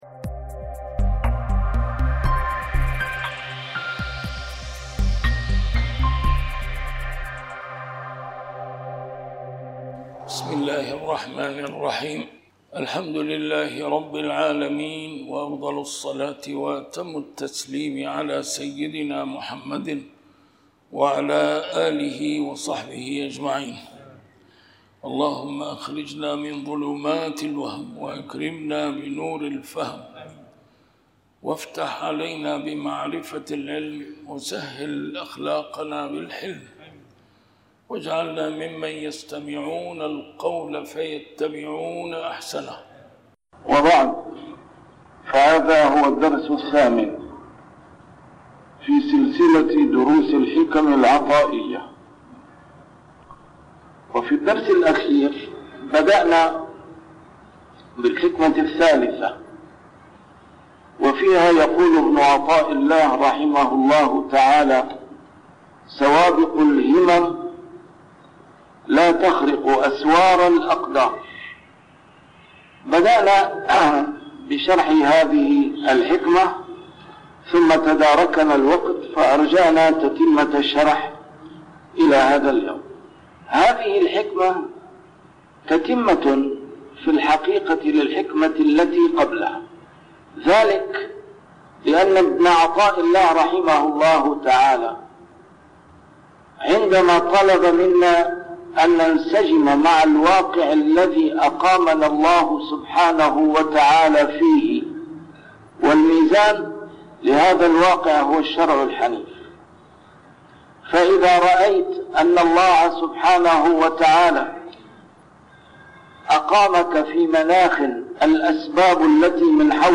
A MARTYR SCHOLAR: IMAM MUHAMMAD SAEED RAMADAN AL-BOUTI - الدروس العلمية - شرح الحكم العطائية - الدرس رقم 8 شرح الحكمة 3